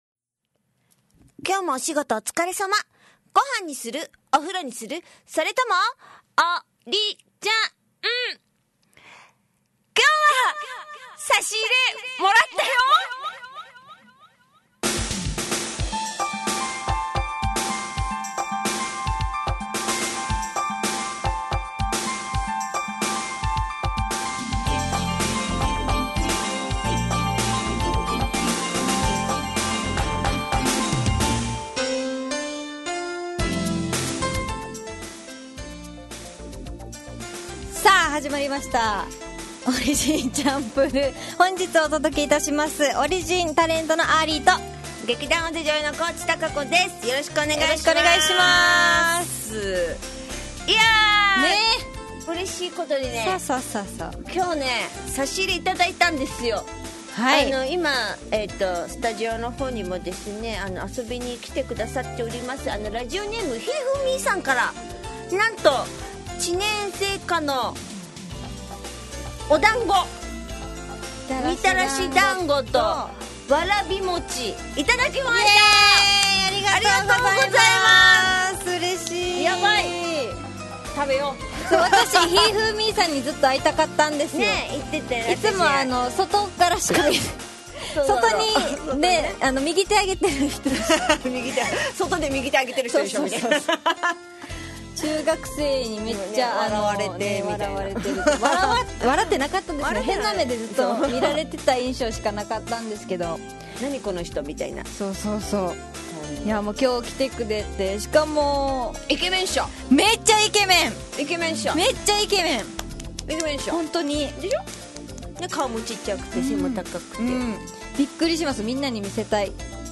fm那覇がお届けする沖縄のお笑い集団オリジンと劇団O.Z.Eメンバー出演のバラエティ番組